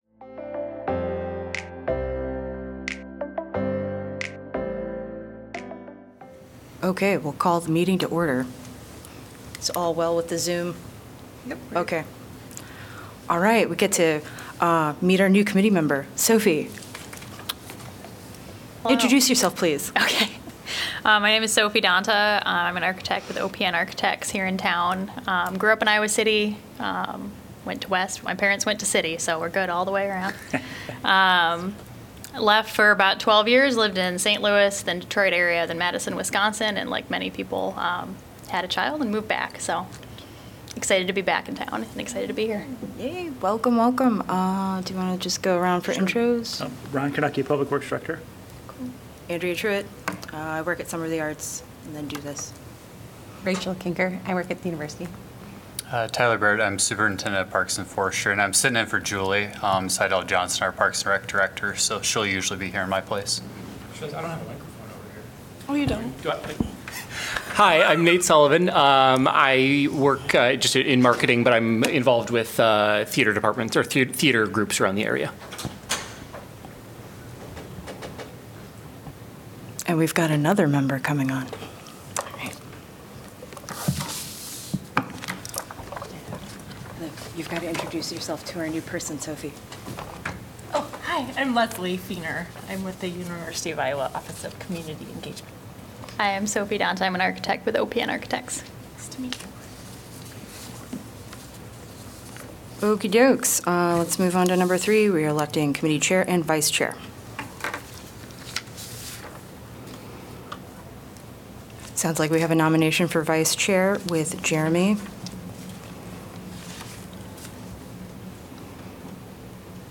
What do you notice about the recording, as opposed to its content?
The regular monthly meeting of the Public Art Advisory Committee.